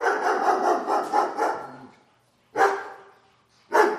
Dog Bark
Dog Bark is a free animals sound effect available for download in MP3 format.
036_dog_bark.mp3